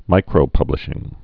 (mīkrō-pŭblĭ-shĭng)